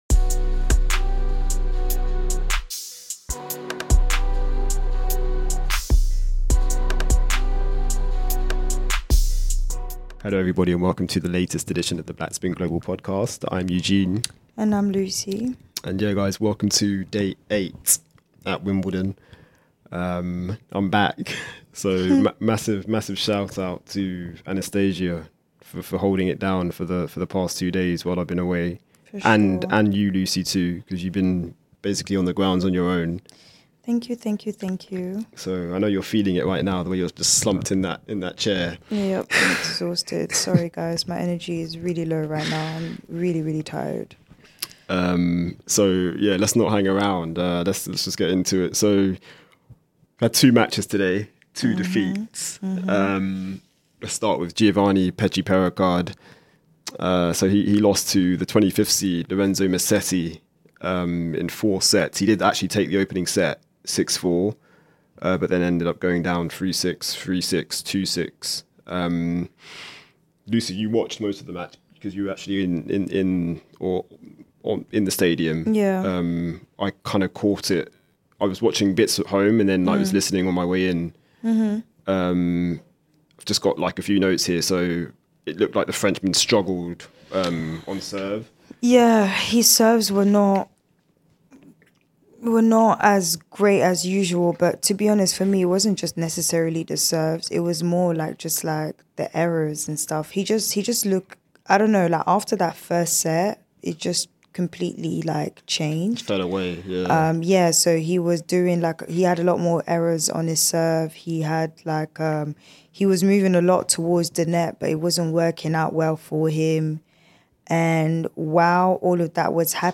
Listen out for an interview snippet from Musetti who spoke about what it was like facing Mpetshi Perricard’s serve. We preview Jasmine Paolini’s quarter-final against Emma Navarro on Centre Court on Day 9 and highlight some juniors.